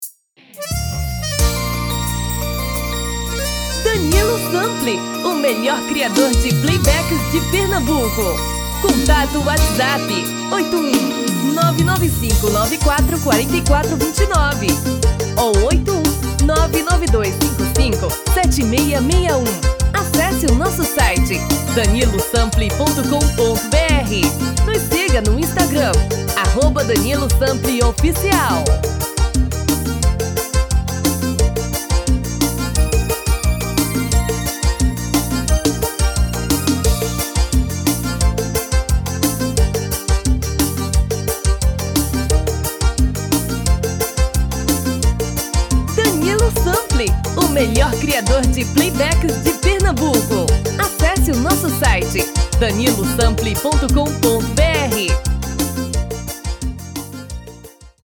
DEMO 1: tom original / DEMO 2: dois tom abaixo